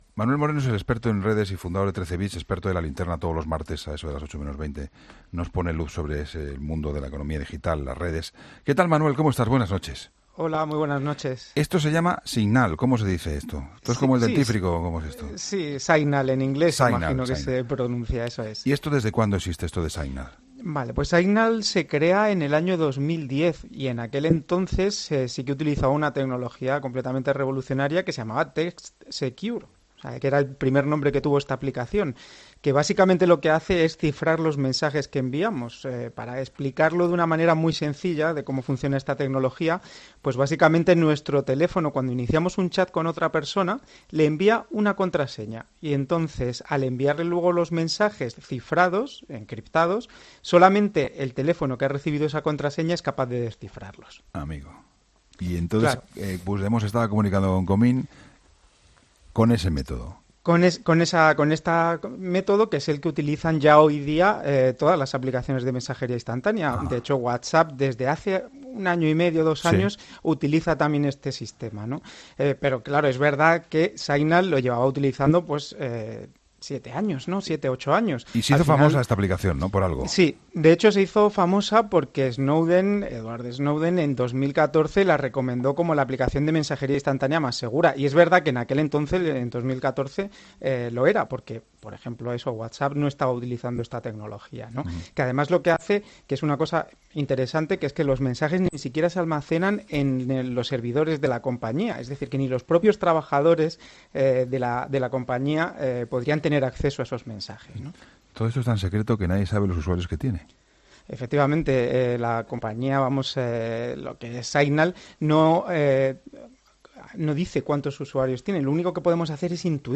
ha participado en la tertulia de este miércoles.